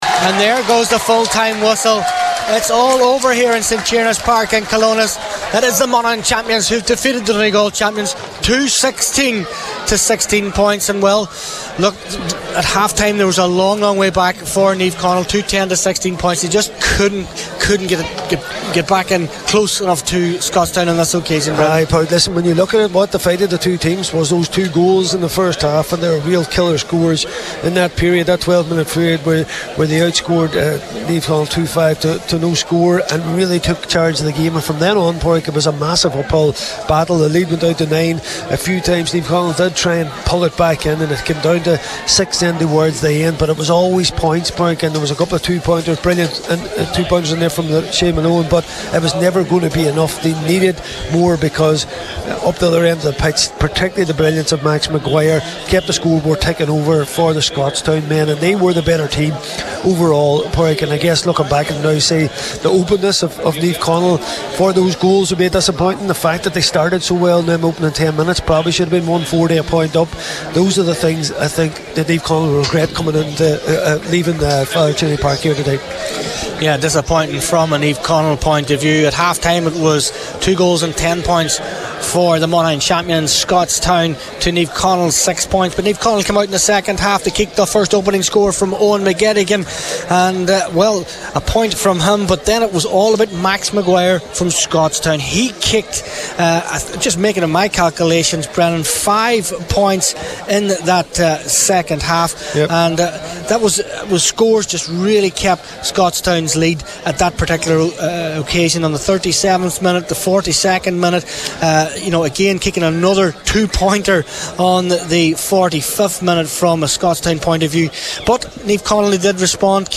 were live at full time for Highland Radio Sport…